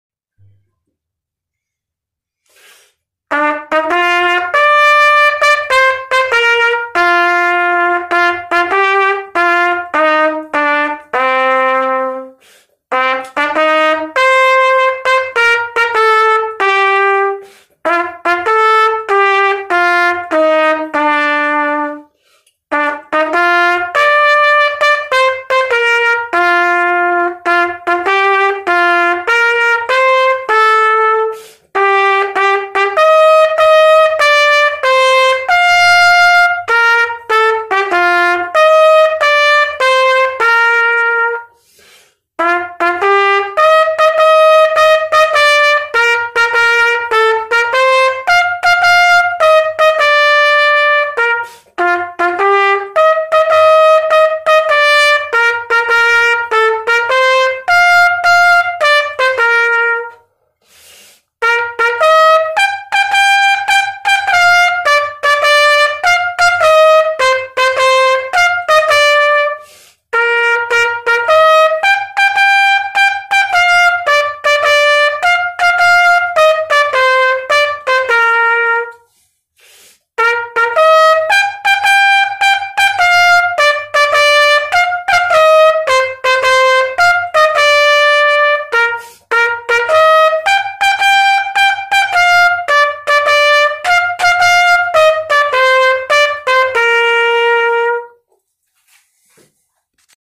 исполненный на трубе